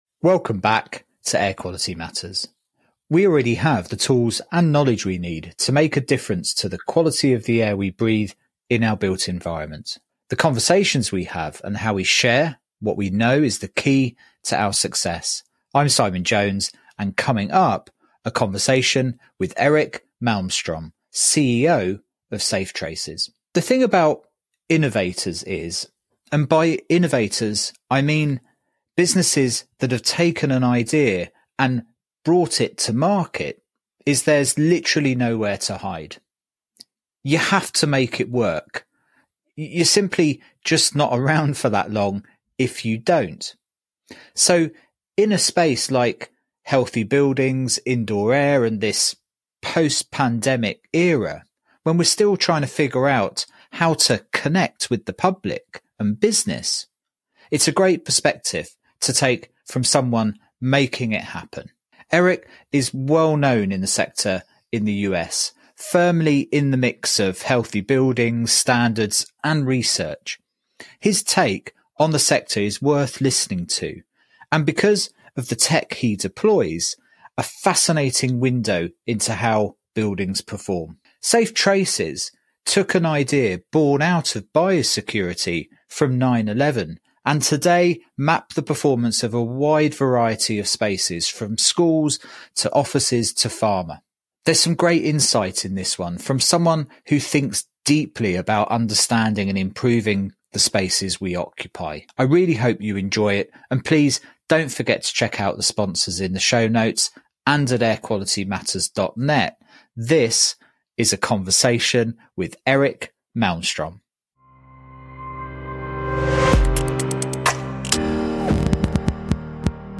In this eye-opening conversation